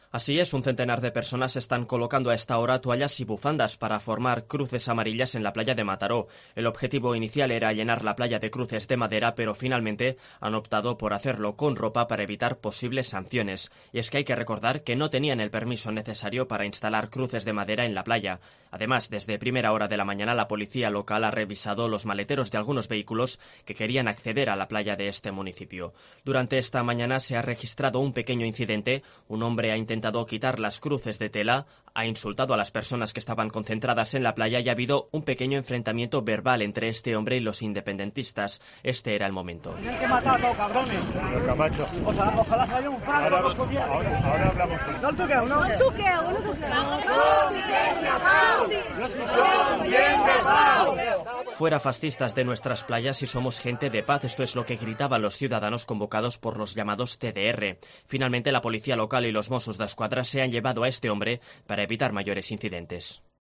Incidentes en la colocación de bufandas cruces en Mataró. Crónica Barcelona